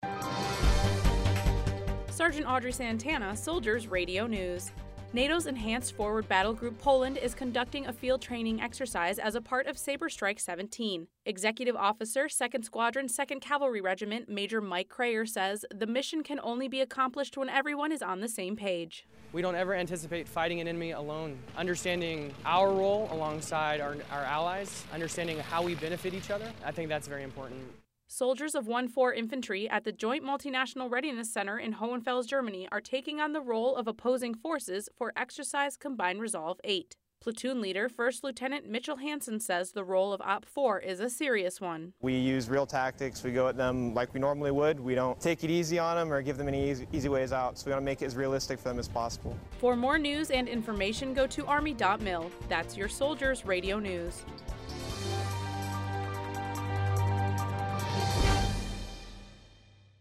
Soldiers Radio News